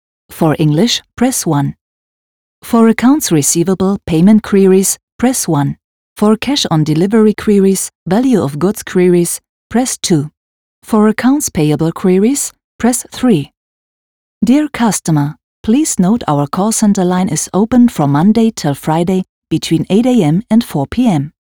Çeşitli reklamlarda seslendirme yapmıştır.